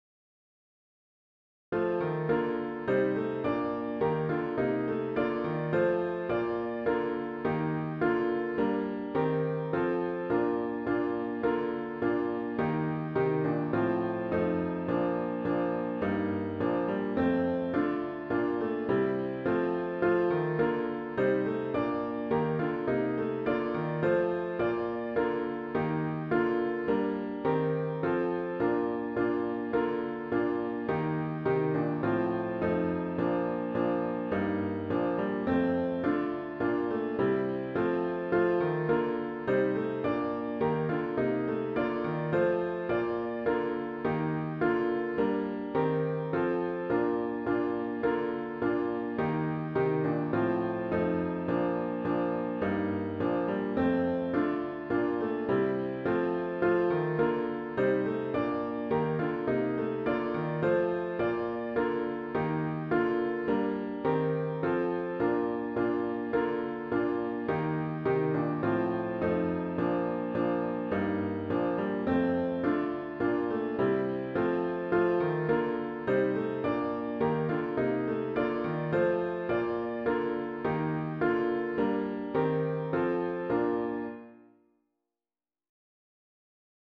HYMN “On Jordan’s Bank the Baptist’s Cry” GtG 96